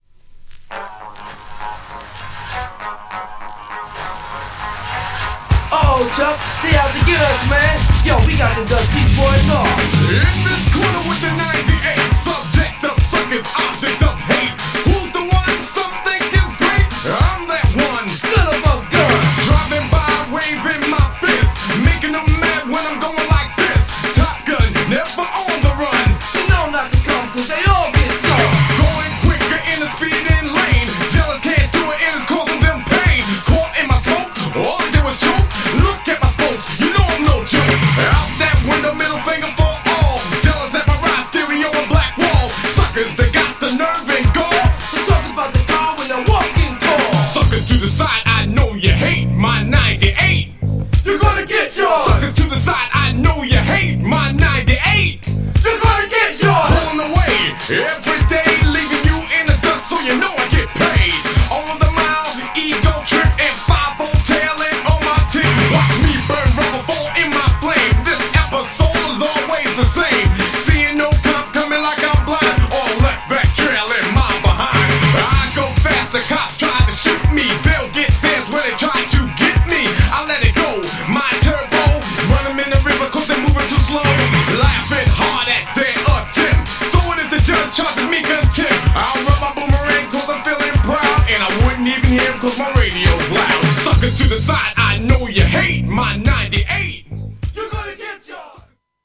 GENRE Hip Hop
BPM 101〜105BPM